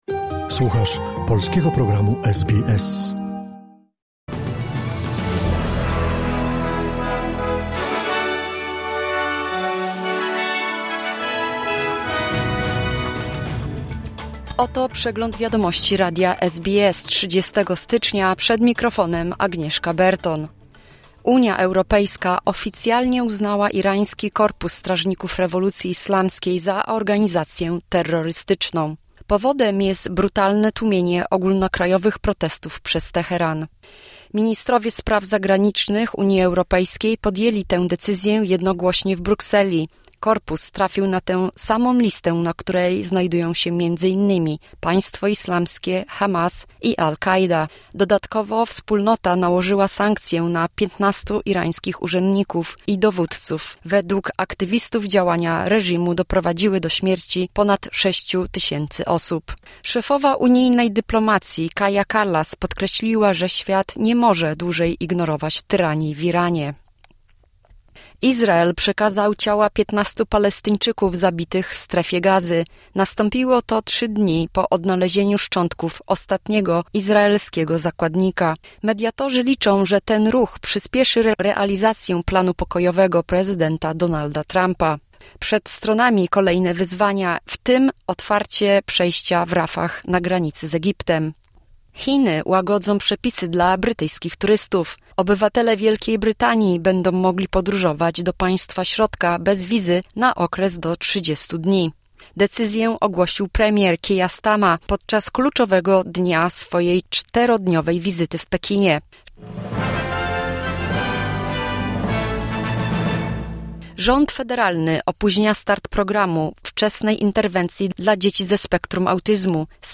Wiadomości 30 stycznia 2026 SBS News Flash